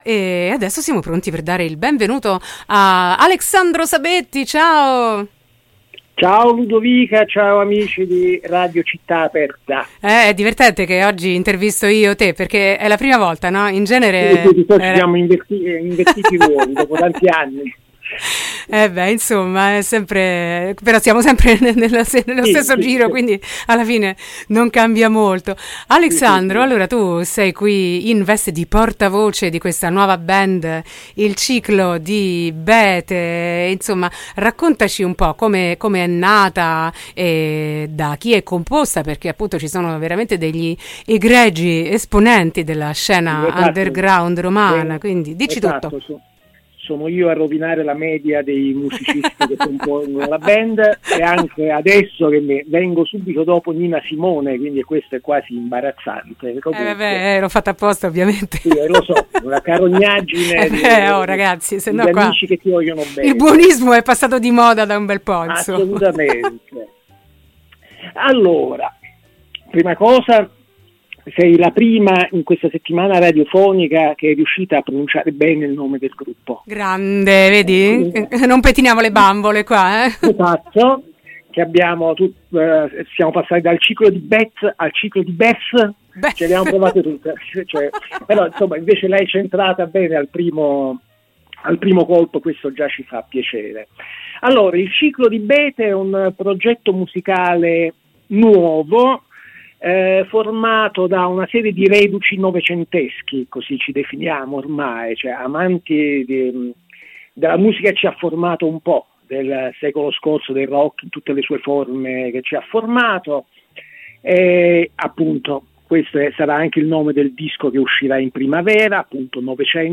intervista-il-ciclo-di-bethe-10-2-23.mp3